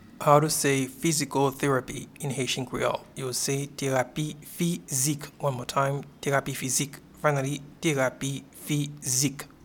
Pronunciation and Transcript:
Physical-therapy-in-Haitian-Creole-Terapi-fizik.mp3